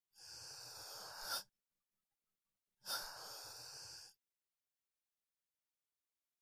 Breath; Weak And Wheezing